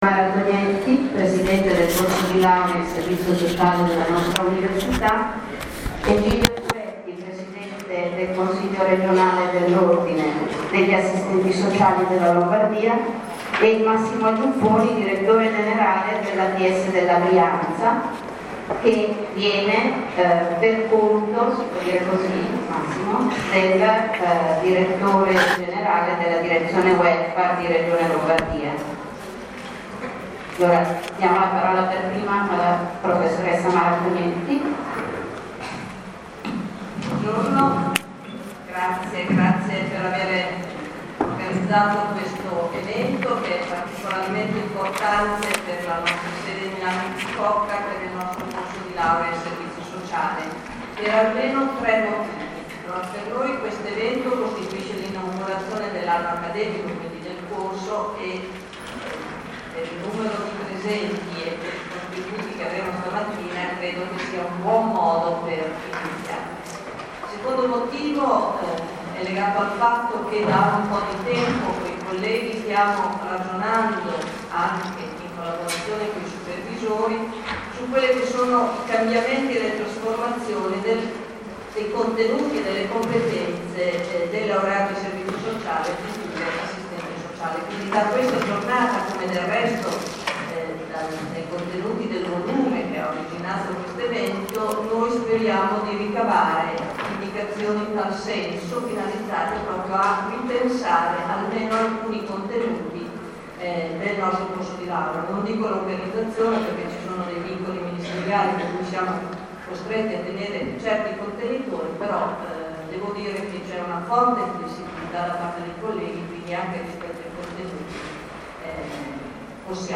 Seminario: Sguardi sul servizio sociale, esperienze e luoghi di una professione che cambia, Università di Milano Bicocca, 4 novembre 2016
Interventi di apertura